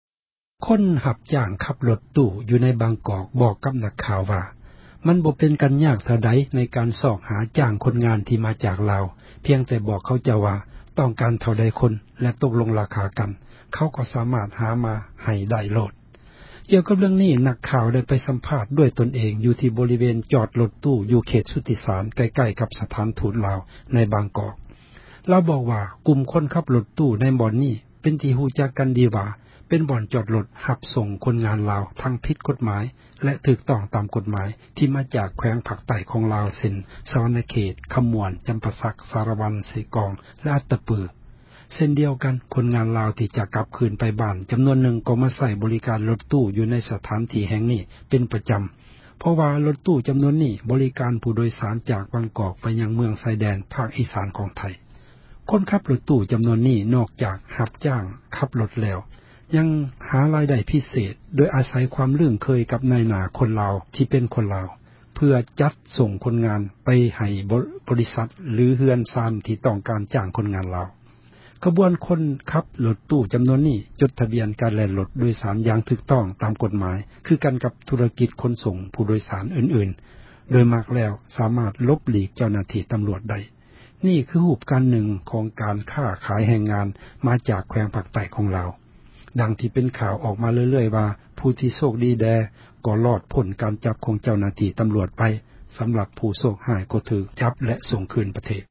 ກ່ຽວກັບເຣື້ອງນີ້ ນັກຂ່າວໄດ້ ໄປສໍາພາດ ດ້ວຍຕົນເອງ ຢູ່ທີ່ ບໍຣິເວນ ຈອດຣົຖຕູ້ ຢູ່ເຂດ ສຸດທິສານ ໃກ້ໆກັບ ສະຖານທູດລາວ ໃນບາງກອກ.